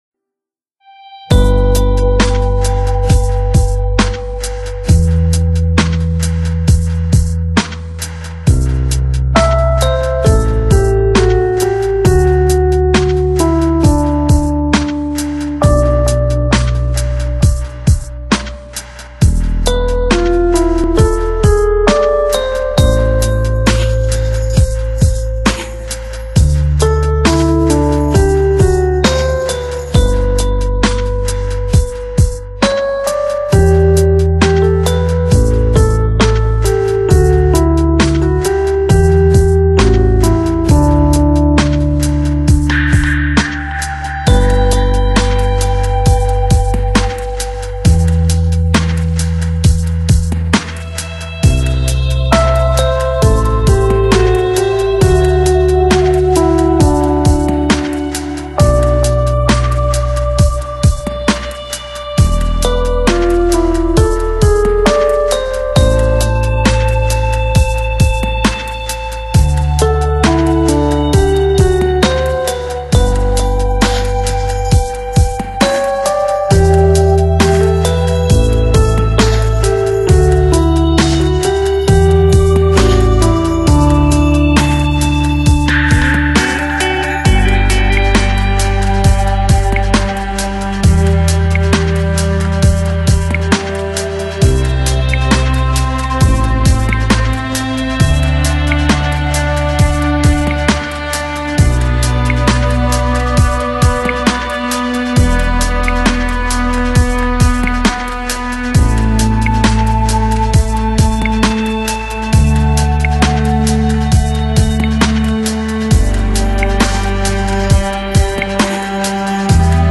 Genre: Downtempo, Lounge, ChillOut